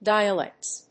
/ˈdaɪ.əˌlɛkts(米国英語), ˈdaɪʌˌlekts(英国英語)/